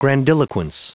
Prononciation du mot grandiloquence en anglais (fichier audio)
Prononciation du mot : grandiloquence